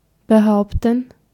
Ääntäminen
IPA : /meɪnˈteɪn/